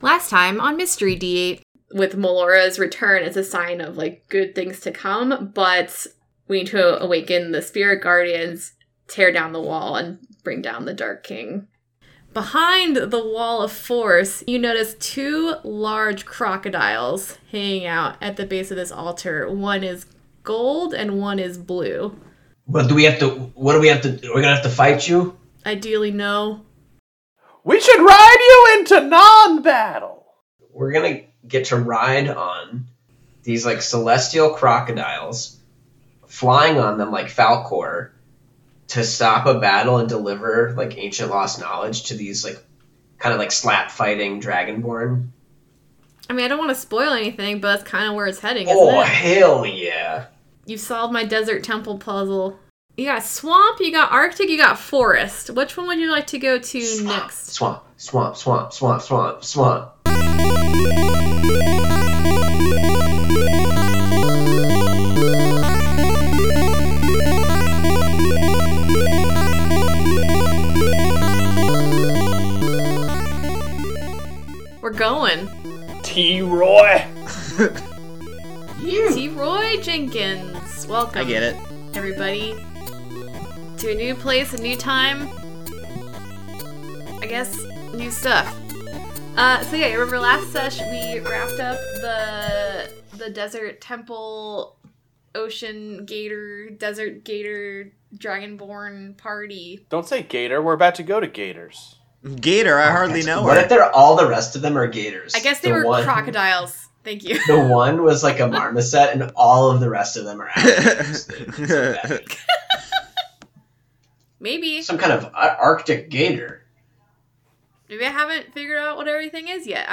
Brewsday Tuesday plays D&D. Really, it's four friends playing D&D together.